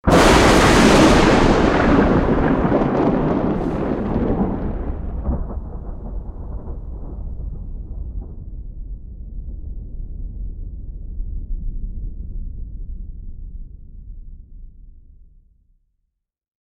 thunder_11.ogg